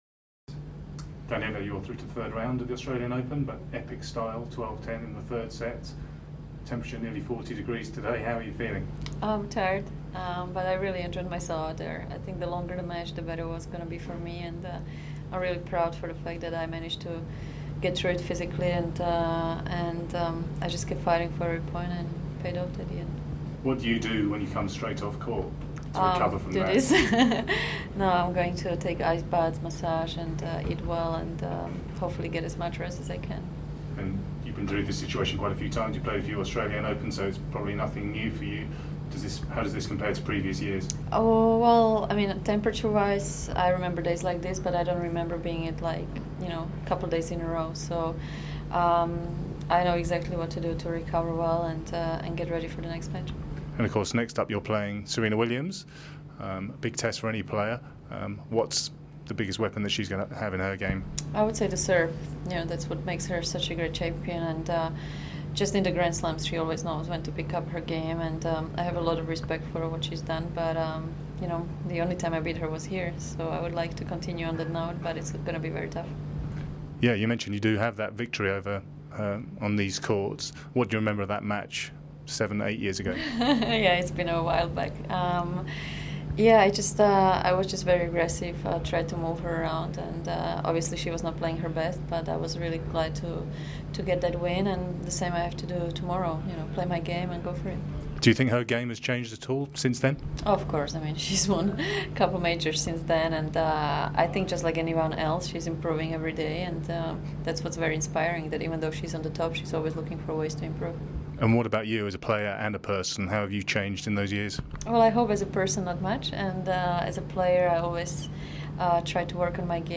A swift chat - it was for TV, it's always swift - about the heat and facing Serena.